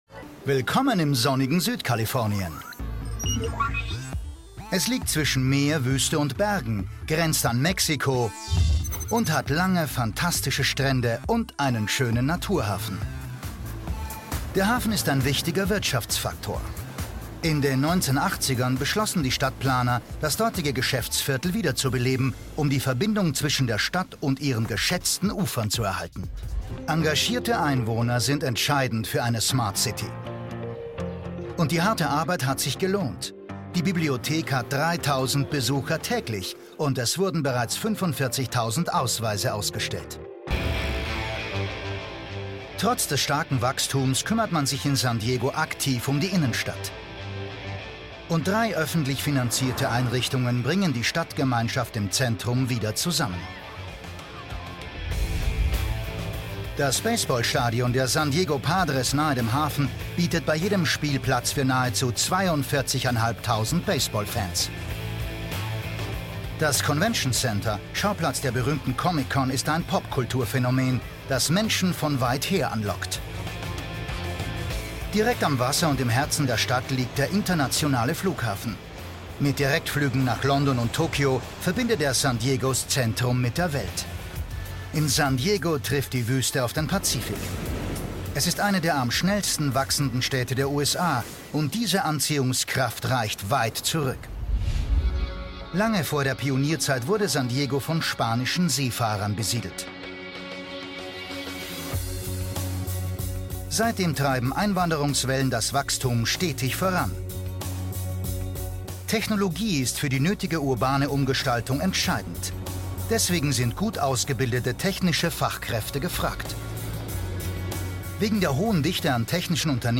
REFERENZEN ll ausgebildeteter Schauspieler mit 40 Jahren Berufserfahrung: TV / Film / Serie / Theater / Werbung / Synchron Kraftvolle, sonore, lebendige, warme Stimme für: Werbung-druckvoll- Dokumentationen / off voice / Telefonsysteme / Guides ( Museum, Stadtführungen) Hörbuch, Hörspiele / Radiogeschichten / Lyrik / Lesungen Einkaufsradio / Dokusoap / Kinowerbung / Messen Trickstimmen TV-Sende: Arte, SWR, ORF Werbung: Einkaufsradio ( Hauptsprecher), Spots, Off Voice, Synchron, Dokumentation, PC- Games: Lesungen, Moderationen usw. viel, viel für arte Trickstimme ( Kinderpingui ) und vieles mehr Hörbücher, Kinderliteratur.
Sprechprobe: Industrie (Muttersprache):